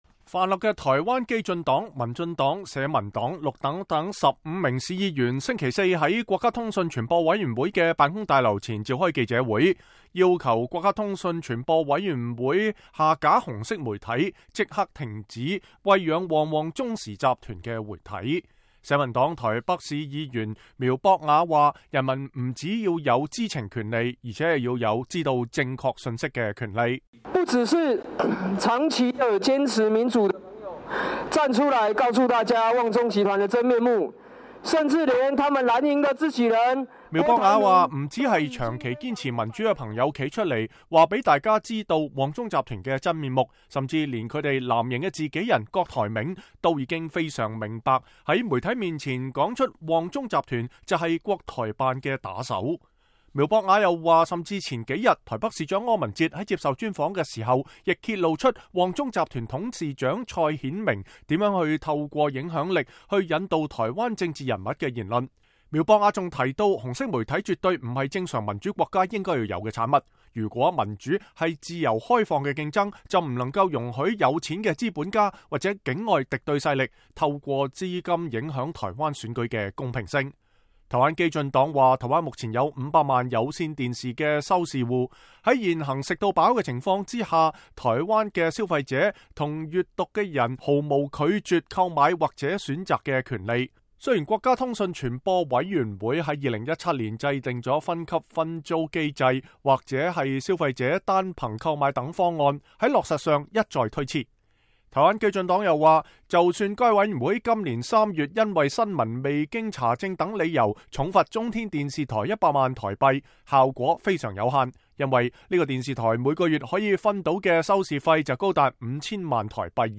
台灣跨黨派市議員及公民團體召開記者會，要求主管機關下架紅色媒體，不要讓台灣成為下一個一國兩制的受害者。